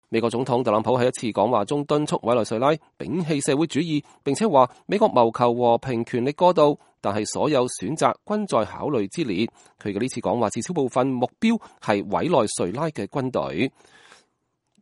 美國總統特朗普2019年2月18日在佛羅里達國際大學對委內瑞拉裔社區講話。